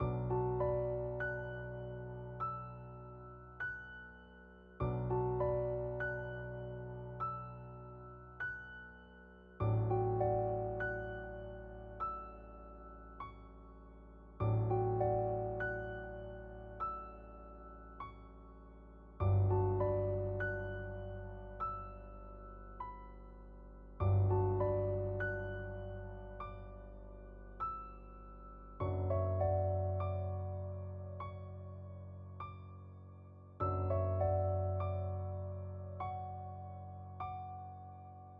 描述：人说话笑唱歌
声道立体声